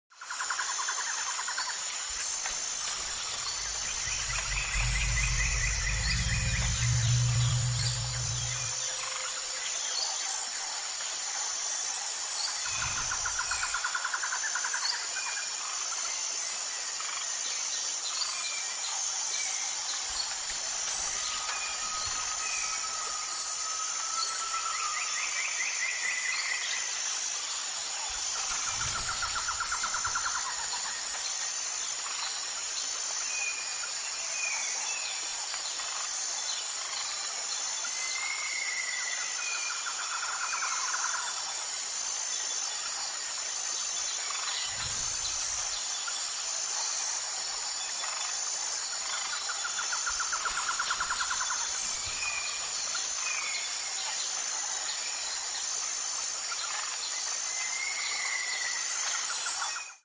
Can you guess what movie this inspired ambience is from?!